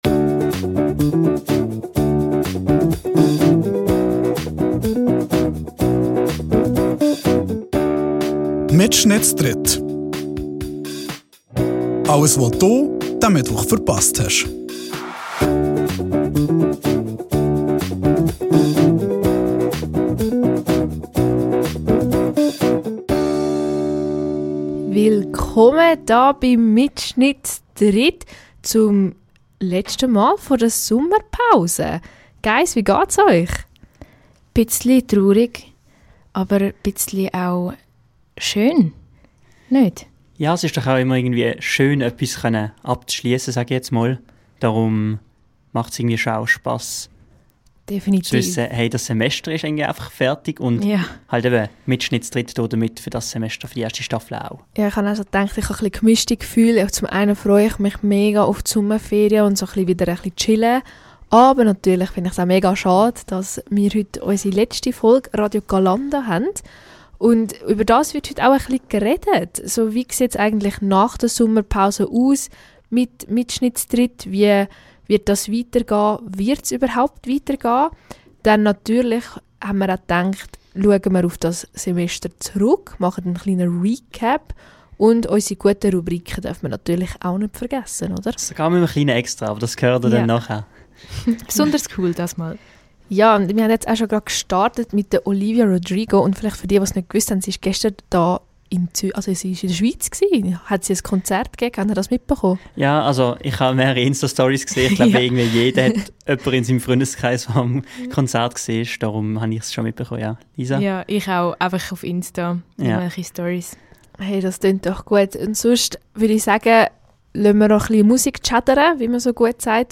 Ih de letschte Sendig vo de erste Staffle sind alli drü Hosts nomal debi. Mir rätsled über Bündner Dialektwörter, rekapituliered eusi Highlights vo MittSchnitt z'dritt und gebed no en chline Usblick uf de kommendi Summer.